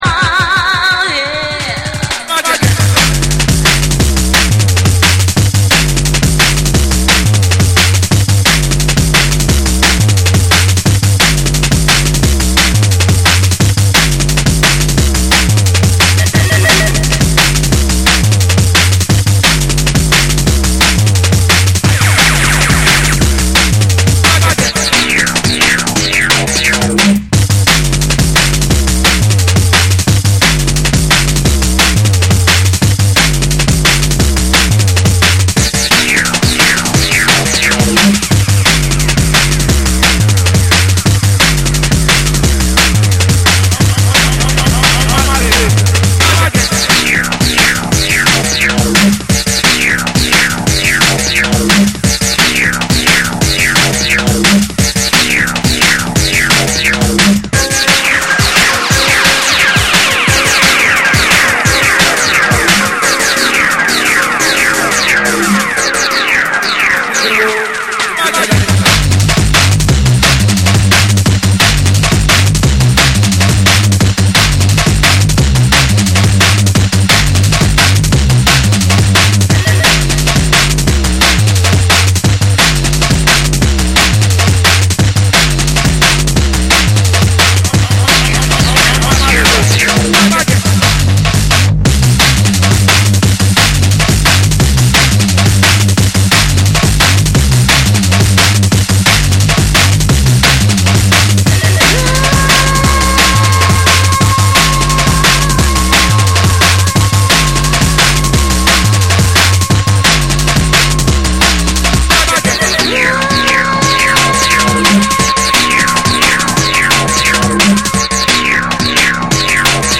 緻密に刻まれるビートと重厚なベースが絡み合い、ダークで硬質なグルーヴを生むドラムンベースを収録。
JUNGLE & DRUM'N BASS